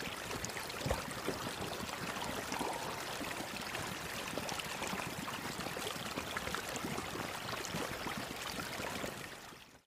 Звуки пираний
Звук плывущей пираньи в открытом водоеме